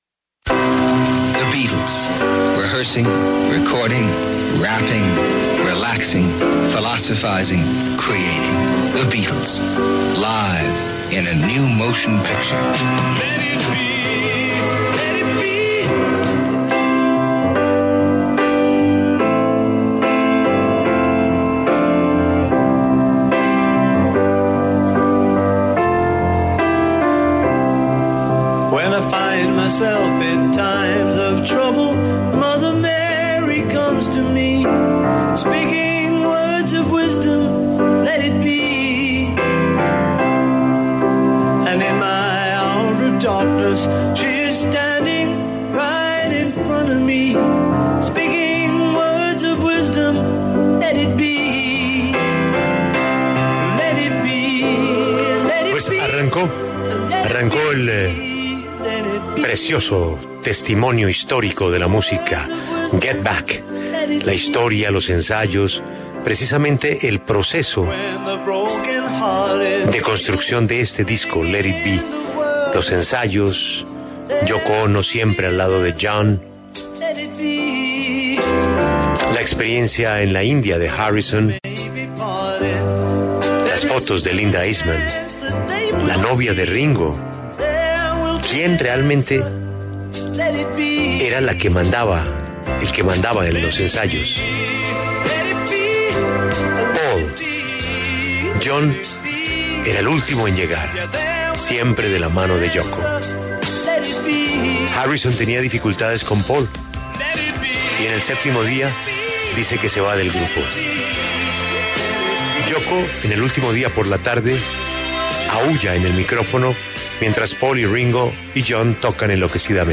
Michael Lindsay-Hogg, director de cine estadounidense, habló en La W sobre su documental que se estrenó hace 51 años y contó su opinión sobre la nueva producción de Peter Jackson.